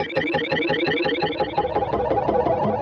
Index of /musicradar/rhythmic-inspiration-samples/85bpm
RI_DelayStack_85-09.wav